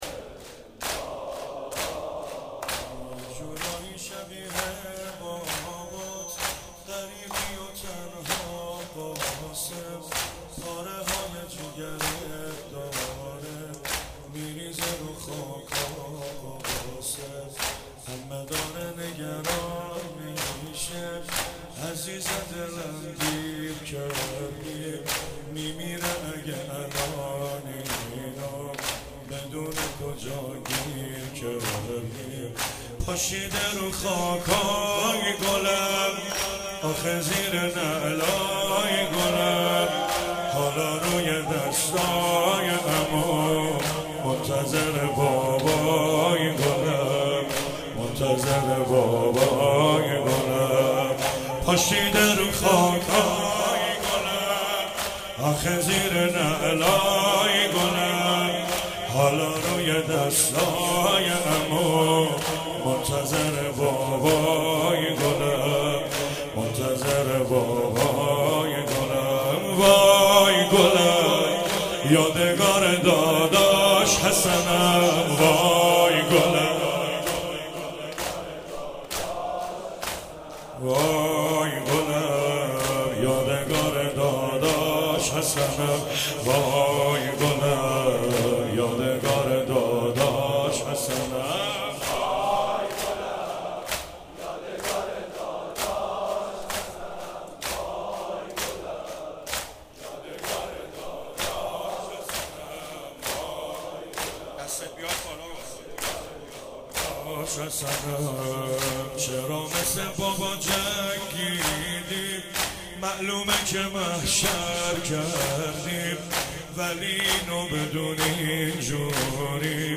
شب ششم محرم 96 -زمینه - یه جورایی شبیه بابات
محرم 96